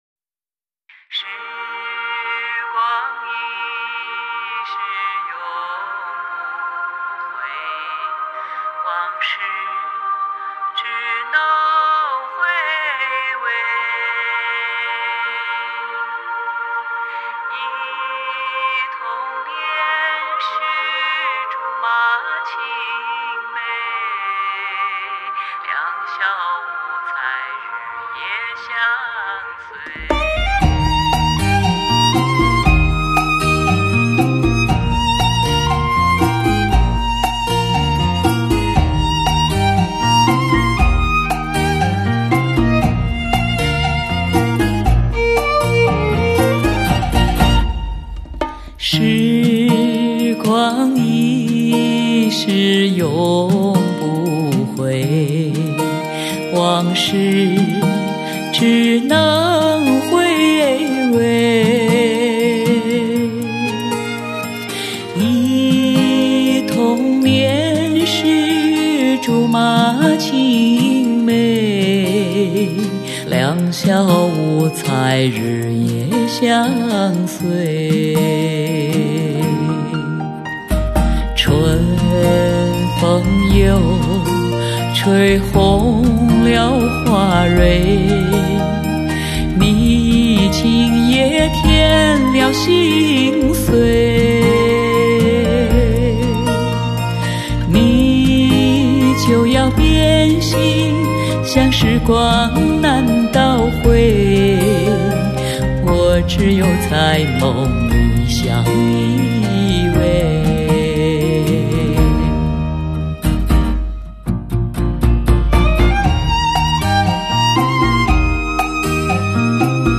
她具有特殊的音质和音色，其音域宽广，低音区深厚、低沉却富有变化，高音区不失敞亮通透。
伴唱